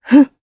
Isolet-Vox_Attack1_jp.wav